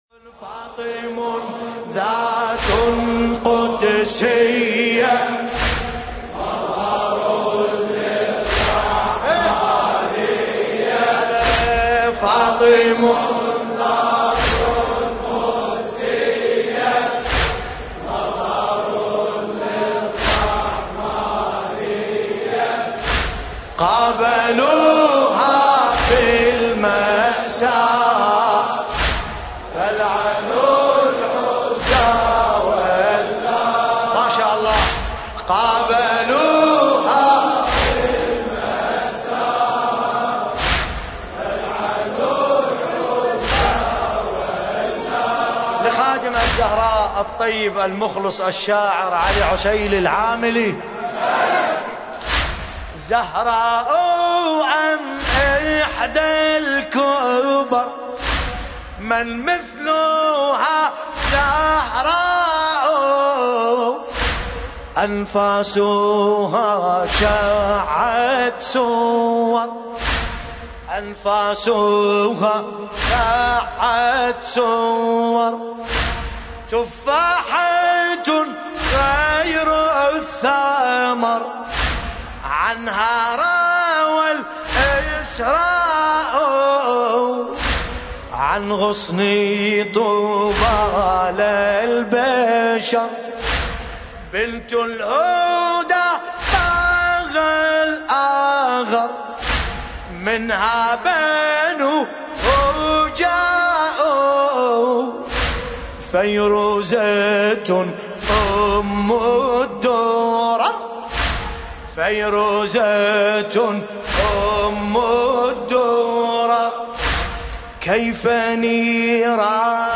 ملف صوتی فاطم ذات قدسية بصوت باسم الكربلائي